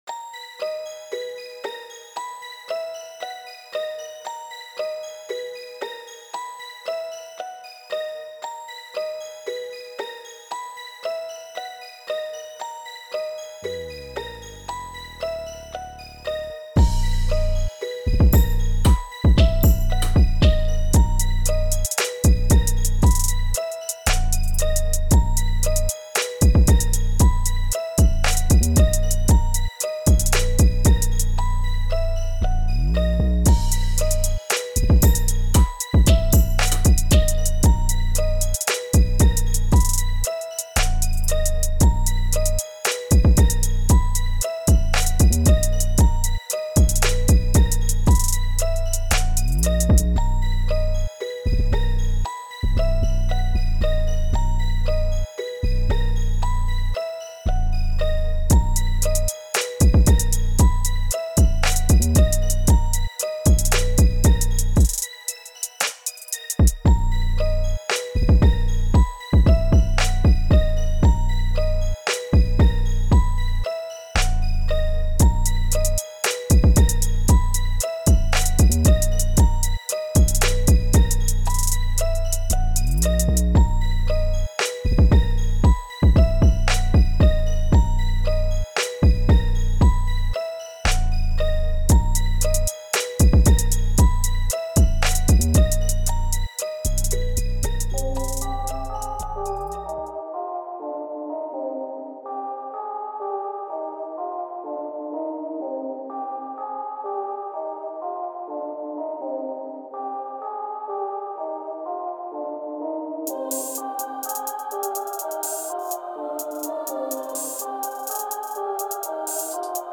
• Mini Construction Kit
• Includes Drums